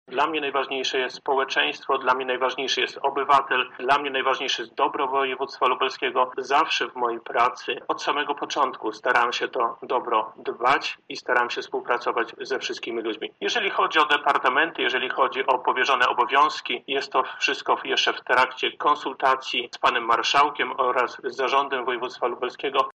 Zawsze byłem uznawany za człowieka dialogu i liczę na współpracę – mówi Bałaban: